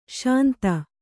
♪ śanta